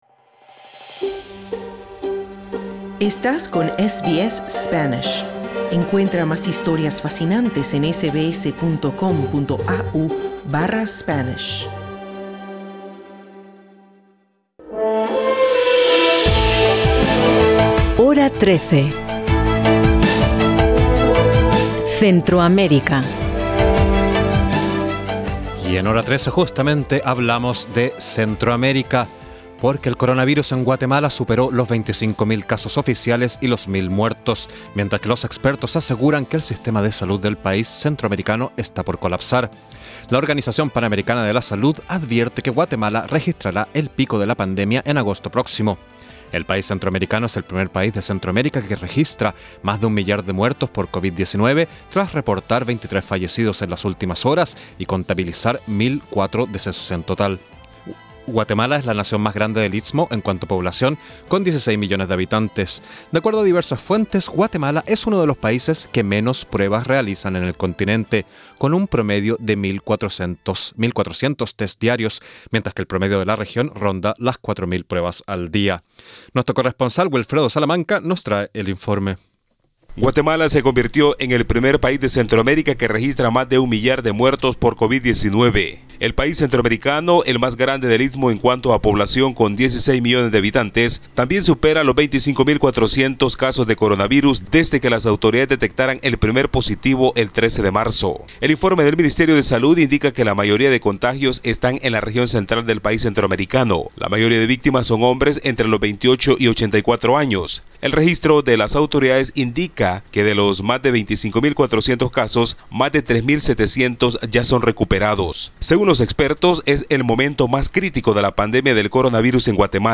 Escucha el informe de nuestro corresponsal en Centroamérica